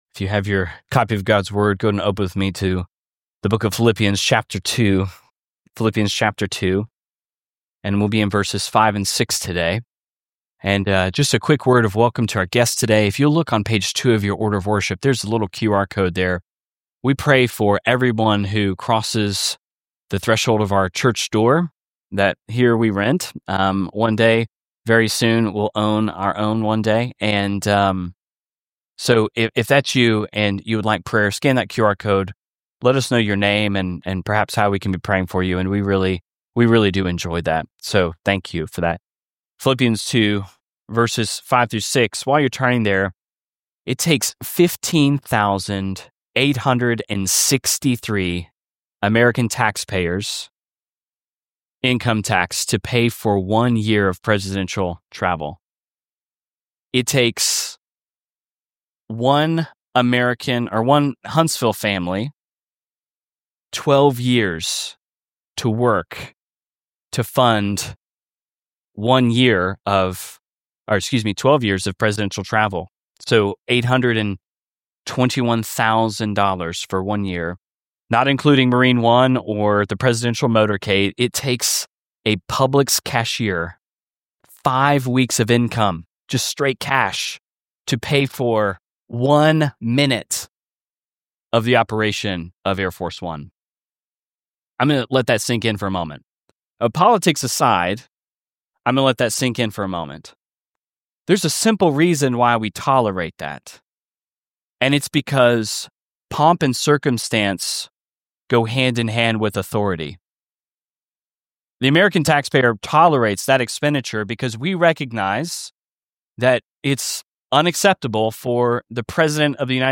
Discover how Jesus, the divine King, humbled Himself to save us—and why His descent shapes how we live. A Palm Sunday sermon from Phil 2:6–7.